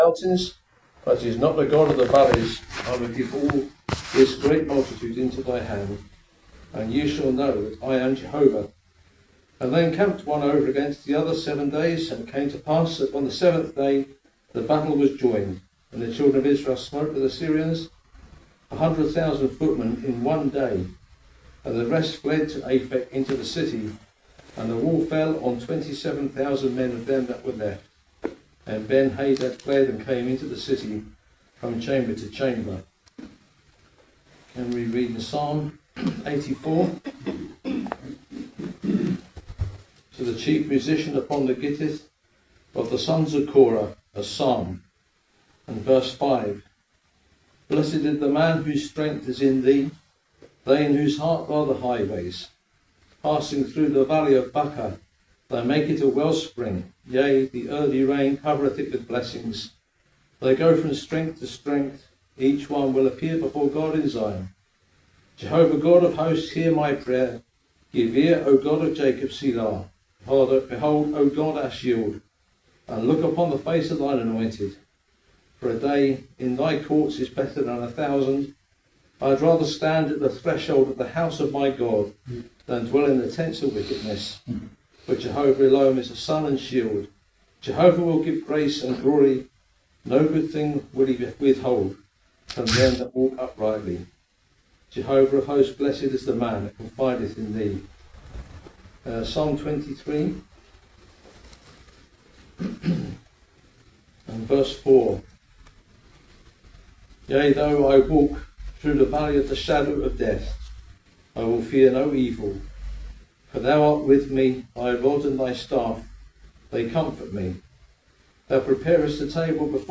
This talk explores the powerful truth that God is also the God of the valleys. Through Scripture, we see how He meets His people in weakness, fear, and broken places—turning valleys of battle, sorrow, and silence into wells of blessing, hope, and renewed strength.